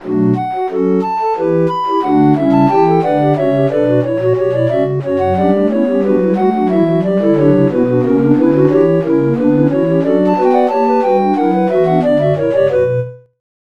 フルートのための超絶技巧曲集。Op.1は、教会音楽みたいな雰囲気に仕上げました。